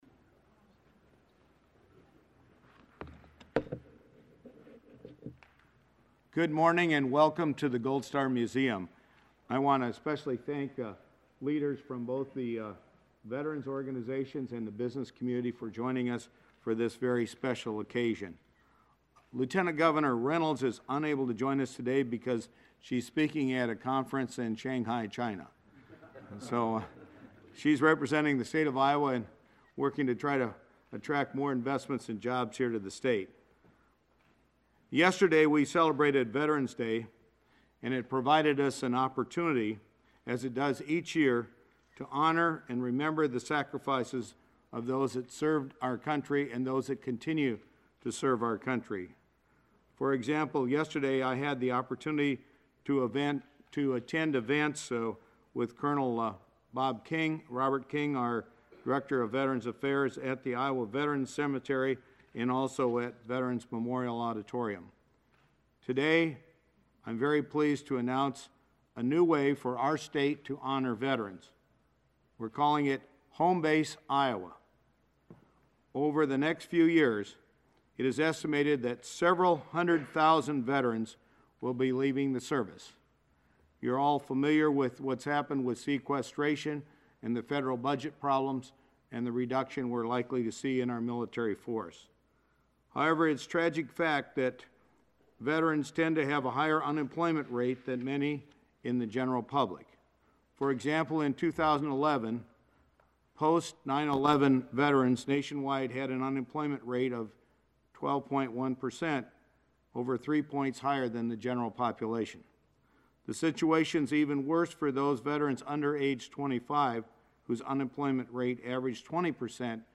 Governor Branstad announces the Home Base Iowa program.